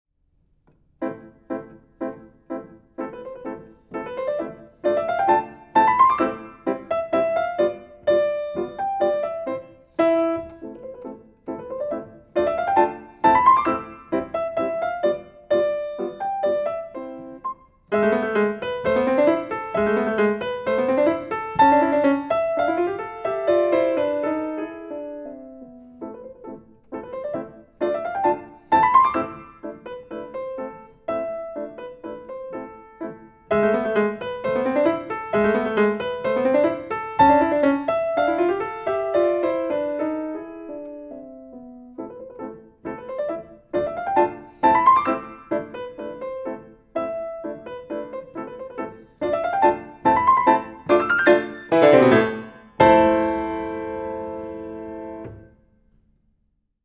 Listen to Lang Lang playing